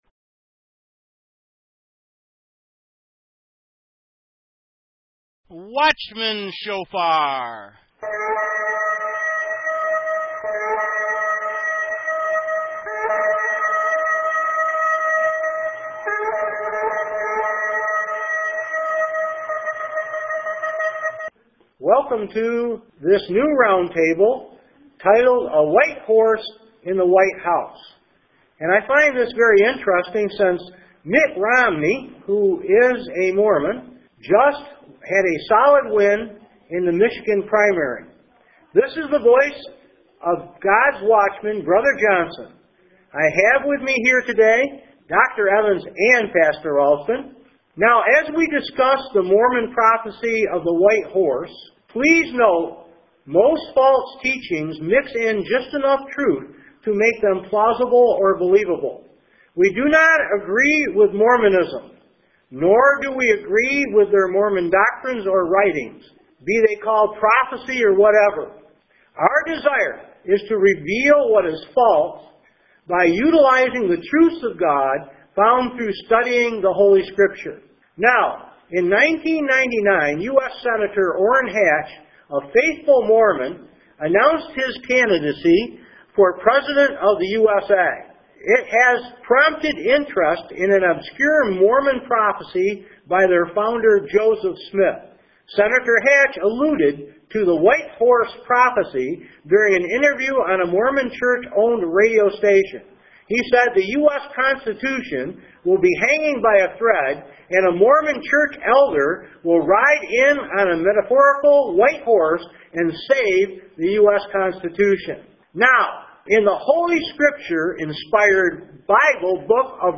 Message Details: Roundtable: White Horse in the White House though Romney dropped out it is still interesting